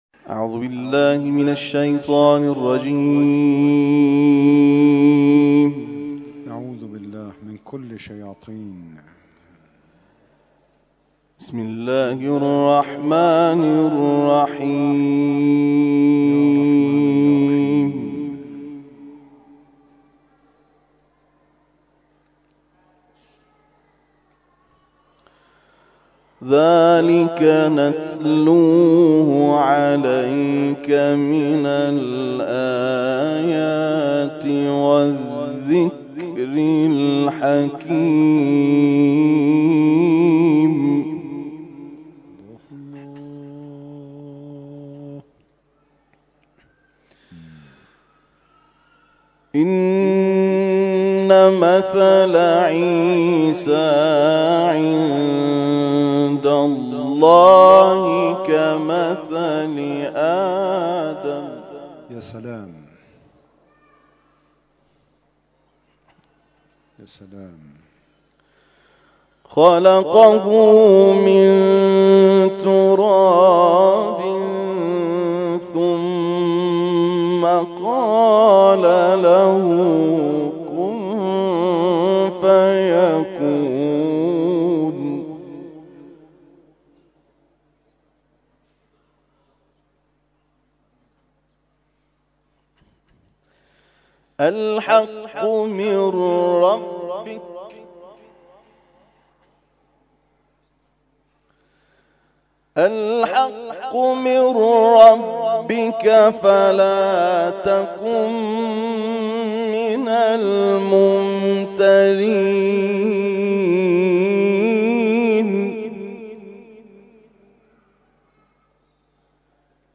دوهزارمین محفل آستان عبدالعظیم(ع):
در ادامه تلاوت های این محفل ارائه می‌شود.